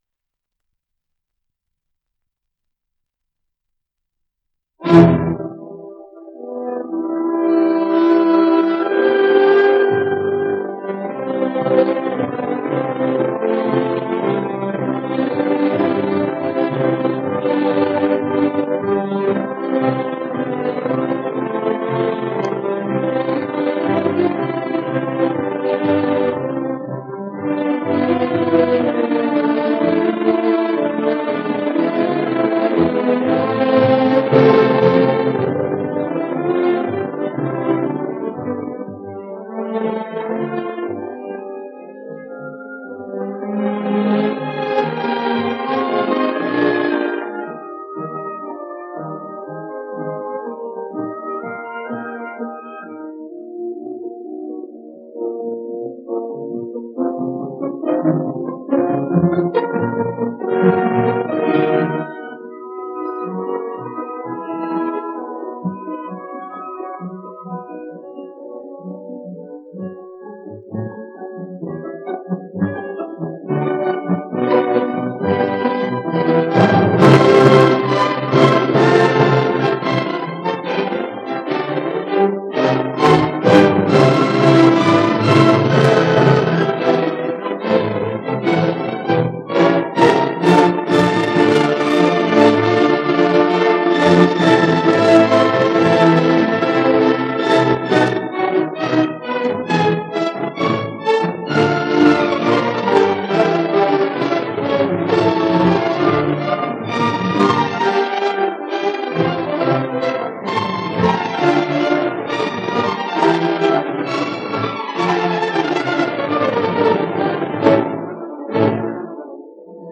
Sinfonía nº 1 en do menor, op. 68. Mov. 4º Allegro non troppo ma con brio, parte II (sonido remasterizado)